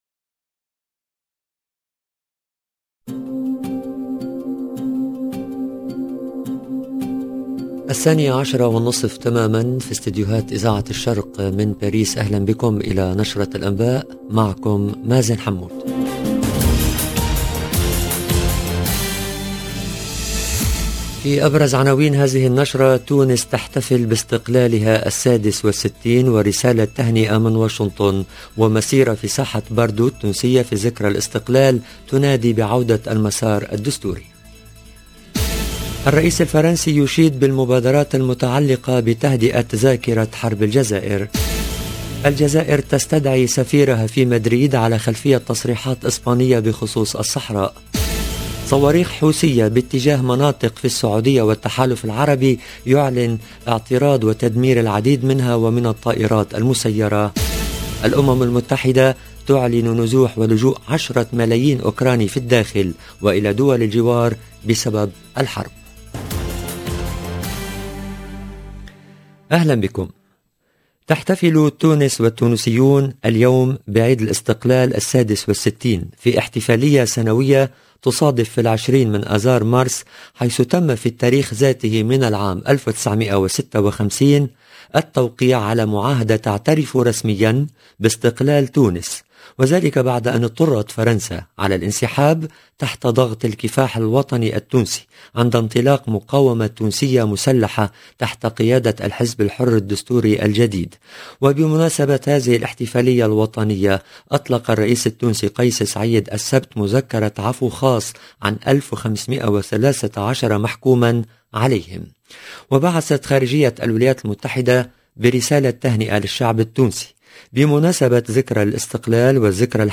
EDITION DU JOURNAL DE 12H30 EN LANGUE ARABE DU 20/3/2022